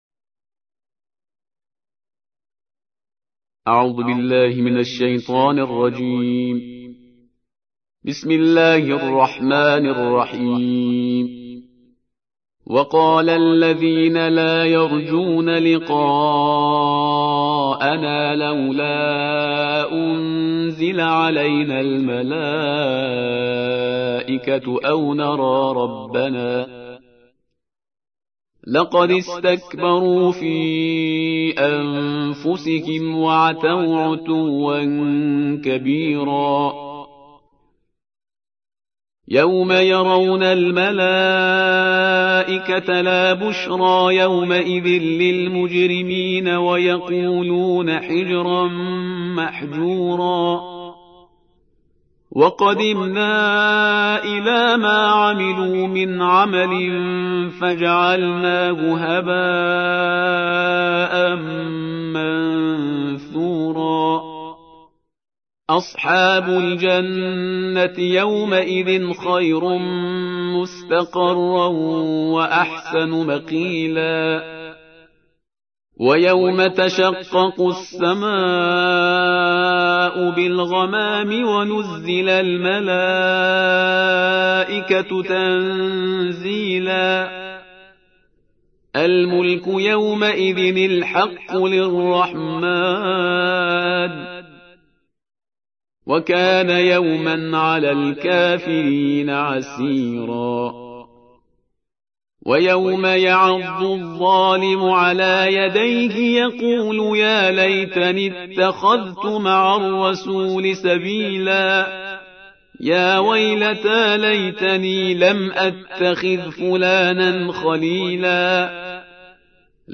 الجزء التاسع عشر / القارئ